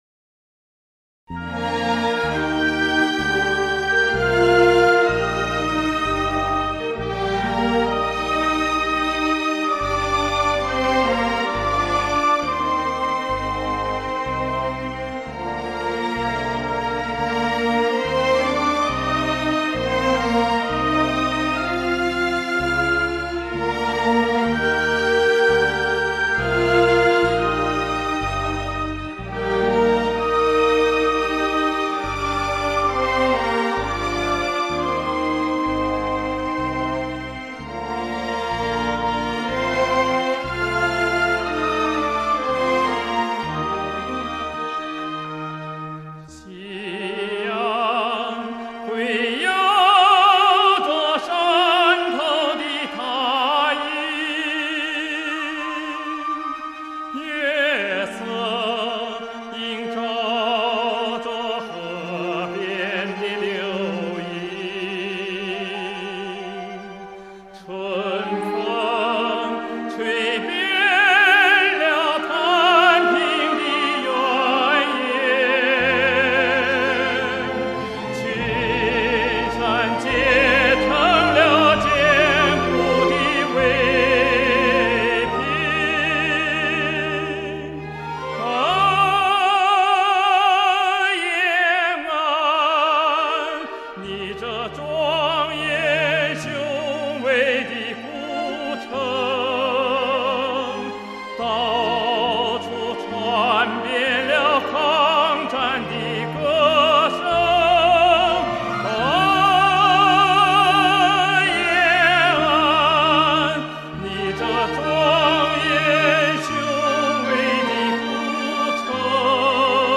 红色经典历史老歌 经过硝烟炮火的歌声 在我们心中重新燃烧起那激昂的旋律